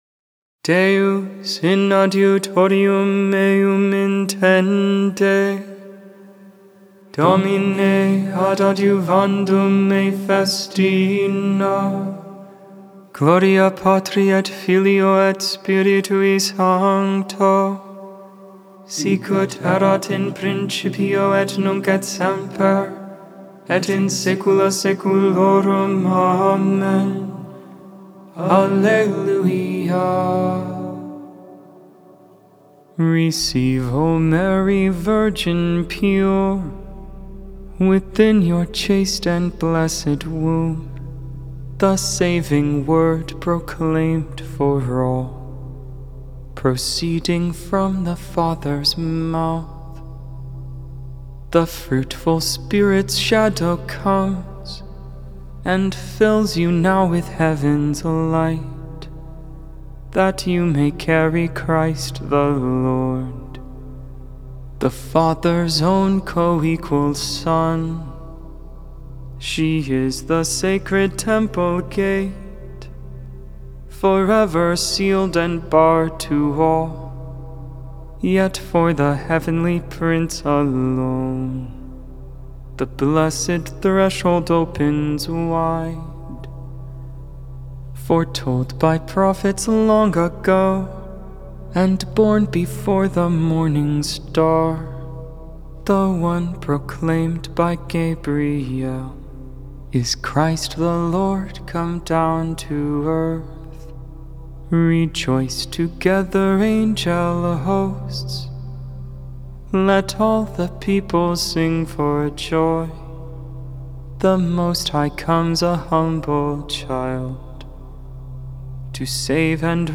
(mode II)Ant.